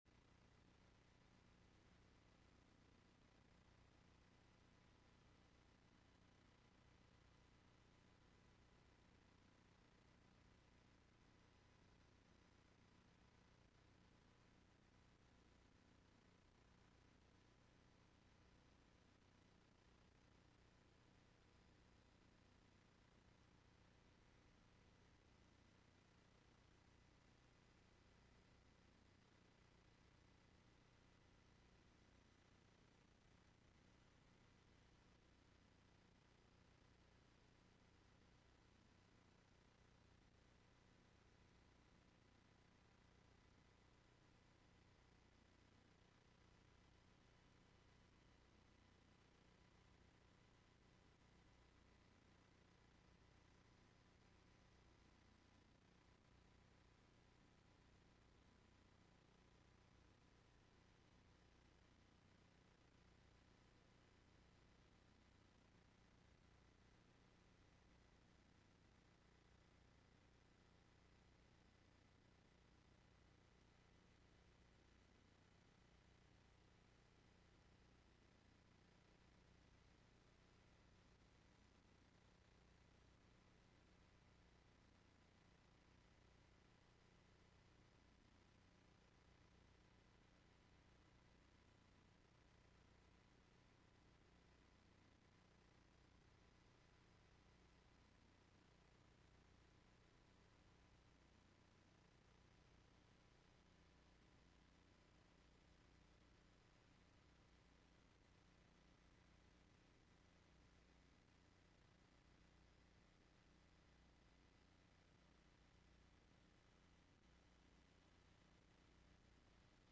Winter Family Weekend Bible Study
This Friday night Bible study was given during the 2022 Winter Family Weekend in Cincinnati, Ohio.